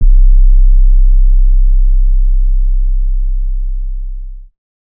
TS 808_5.wav